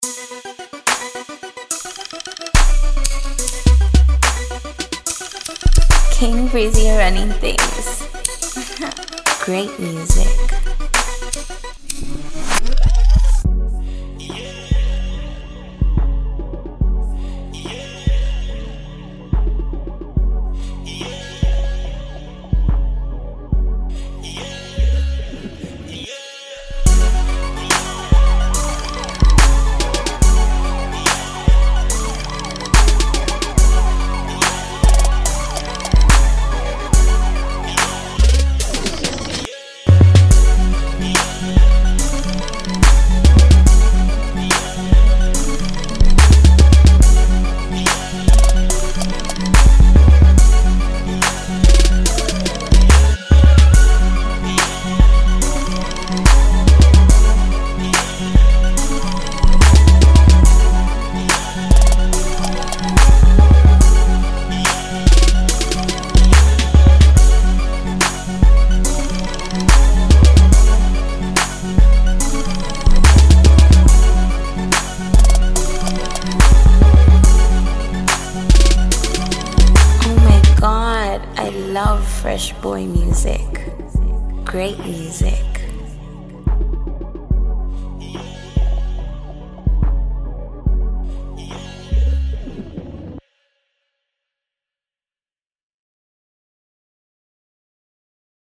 FREE BEATS
the instrumental